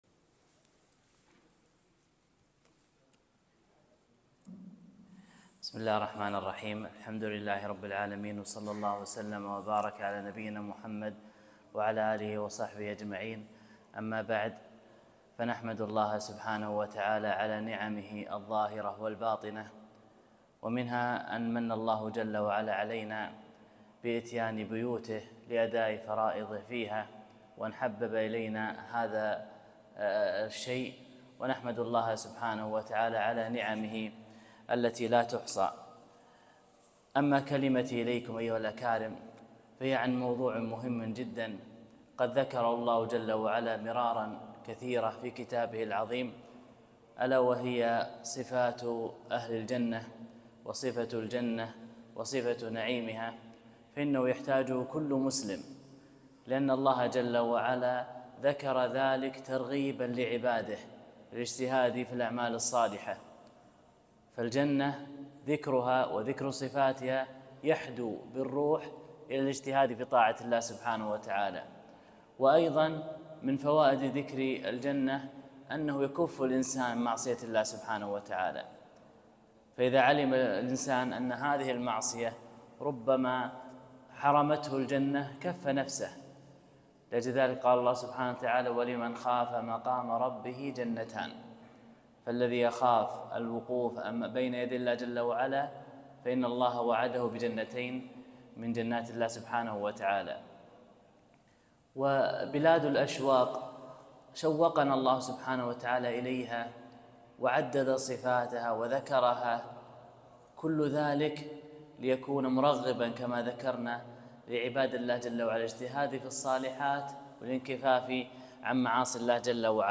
محاضرة - صفة الجنة ونعيمها